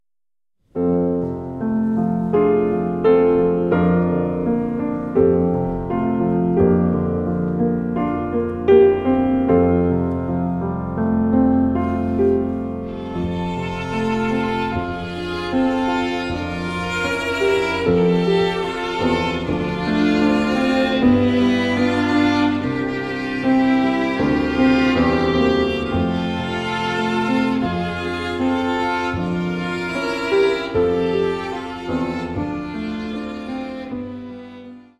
Streicher, Klavier
• kurzweilige Zusammenstellung verschiedener Live-Aufnahmen